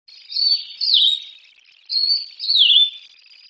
16-5溪頭藪鳥song1.mp3
黃痣藪鶥 Liocichla steerii
錄音地點 南投縣 鹿谷鄉 溪頭
錄音環境 森林
行為描述 鳴唱
收音: 廠牌 Sennheiser 型號 ME 67